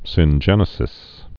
(sĭn-jĕnĭ-sĭs)